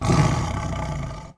sounds_tiger_snarl_01.ogg